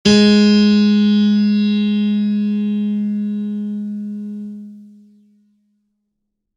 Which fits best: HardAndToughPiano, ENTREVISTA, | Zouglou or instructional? HardAndToughPiano